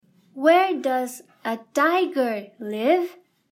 tiger.mp3